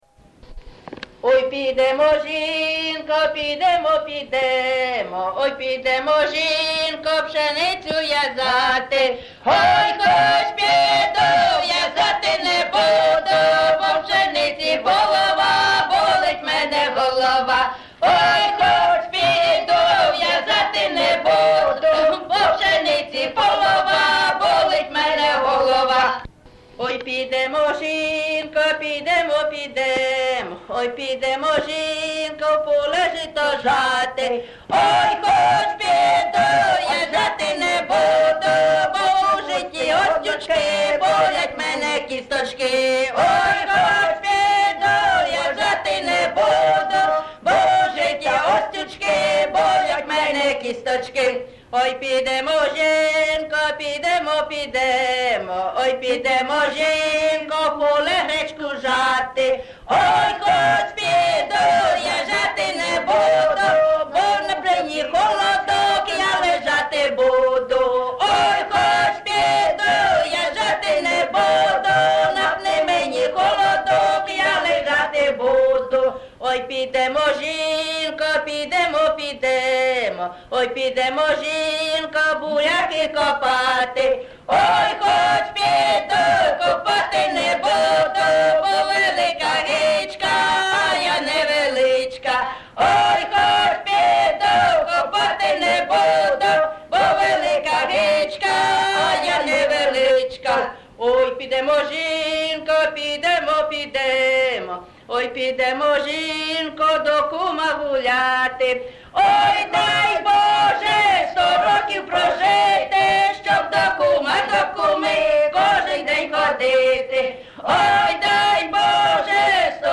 ЖанрЖартівливі
Місце записус. Яблунівка, Костянтинівський (Краматорський) район, Донецька обл., Україна, Слобожанщина